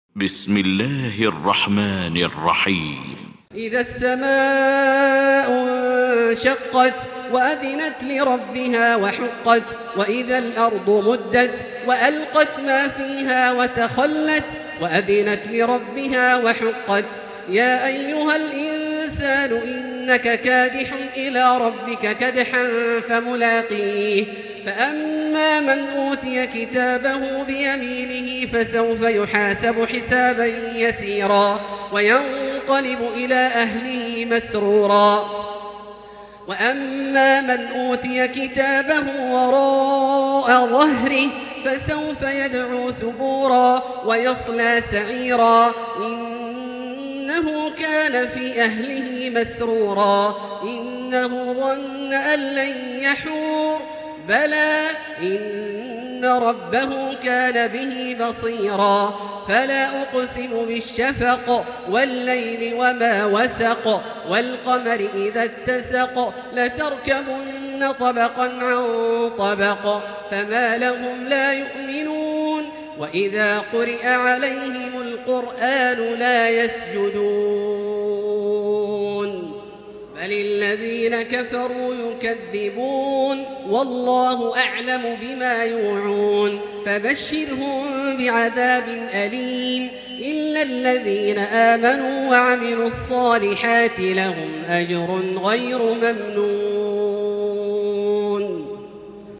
المكان: المسجد الحرام الشيخ: فضيلة الشيخ عبدالله الجهني فضيلة الشيخ عبدالله الجهني الانشقاق